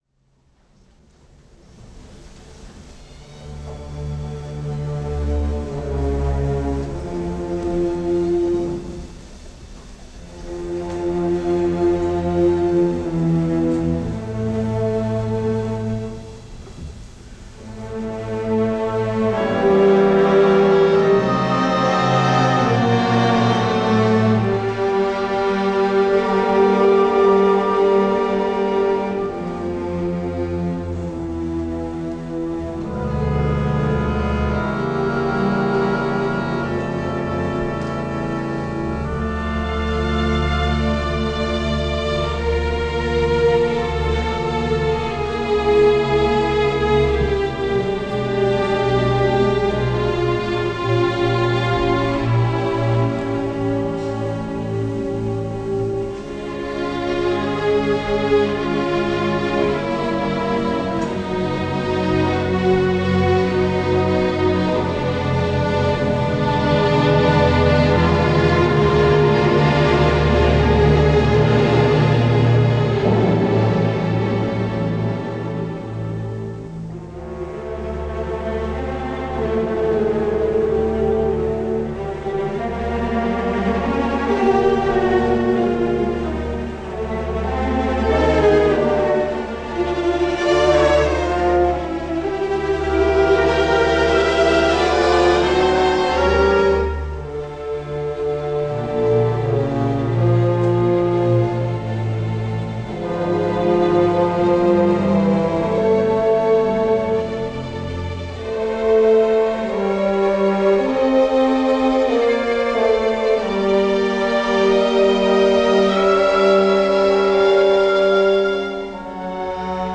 From a broadcast recorded September 21, 1958 from Salle du Pavillon, Montreux by RTS – a performance of Franck’s D Minor Symphony with Orchestre National de la RadioDiffusion Française, conducted by Pierre Monteux.